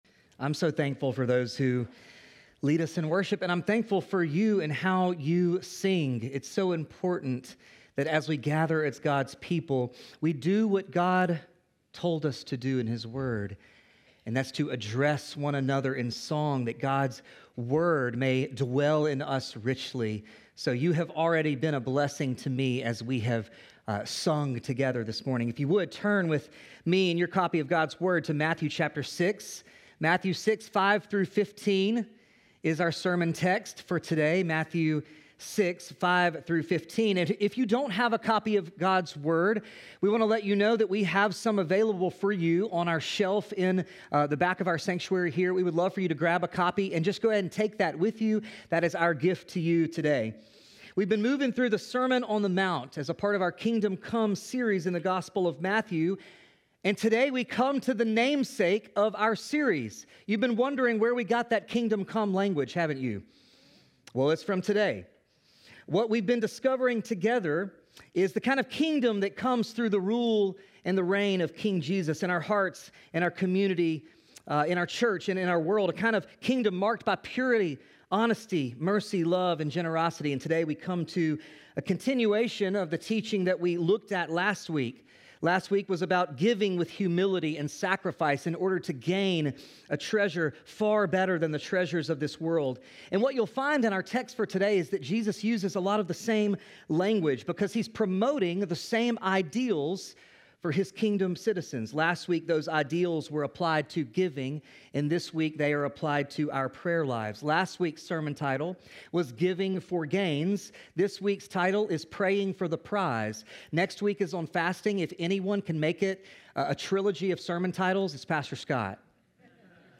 Parkway Sermons